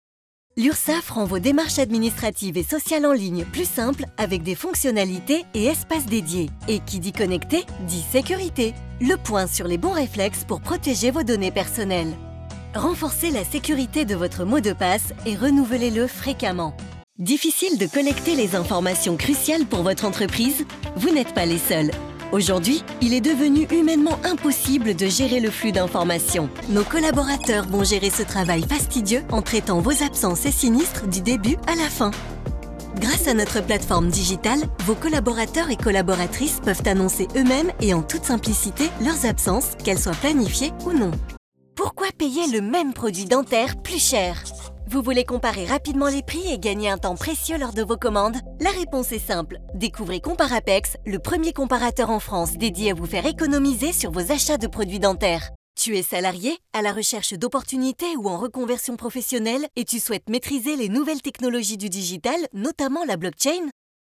Junge, Sanft, Verspielt, Cool, Vielseitig
Unternehmensvideo